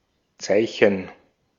Ääntäminen
Ääntäminen Tuntematon aksentti: IPA: [ˈe̞le̞ˣ] IPA: /ˈe.leʔ/ Haettu sana löytyi näillä lähdekielillä: suomi Käännös Ääninäyte Substantiivit 1.